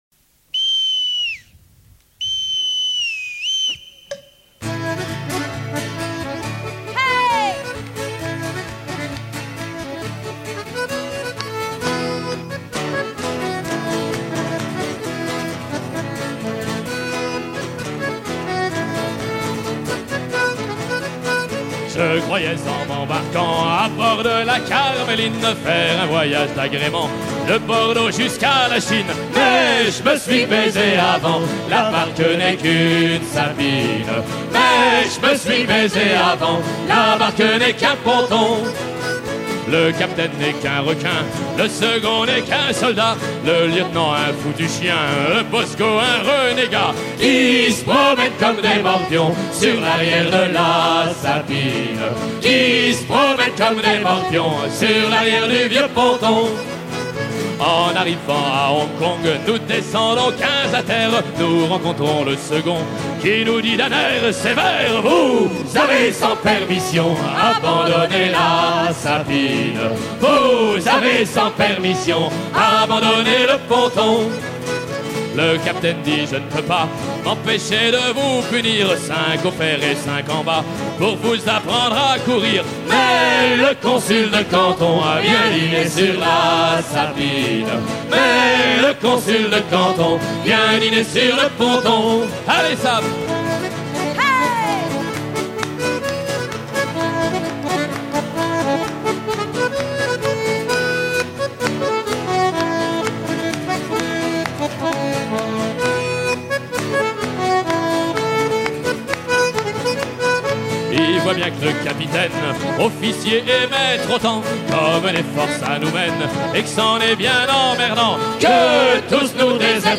gestuel : à virer au cabestan
circonstance : maritimes
Pièce musicale éditée